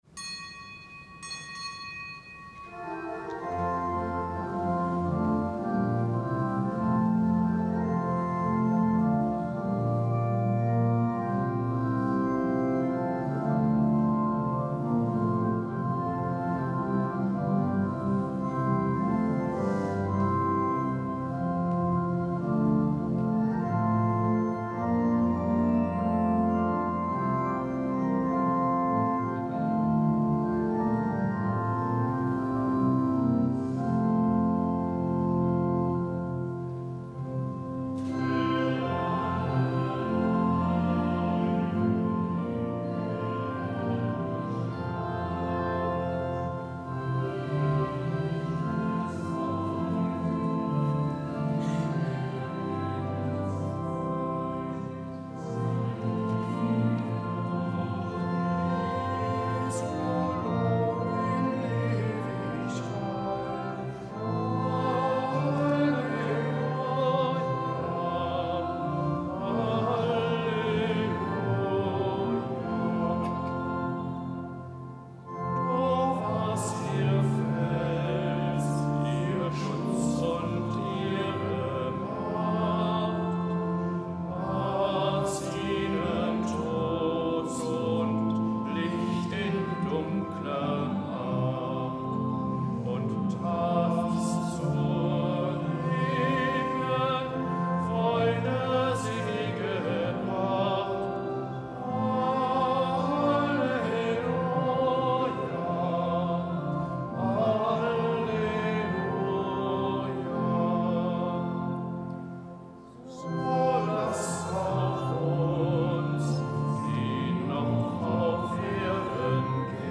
Kapitelsmesse am Mittwoch der zweiten Woche im Jahreskreis
Kapitelsmesse aus dem Kölner Dom am Mittwoch der zweiten Woche im Jahreskreis, Nichtgebotener Gedenktag Heiliger Meinrad, Mönch auf der Reichenau, Einsiedler, Märtyrer (RK) und Heilige Agnes, Jungf